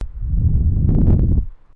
呼吸
描述：香烟后呼吸麦克风。
Tag: 城市 呼吸 吸烟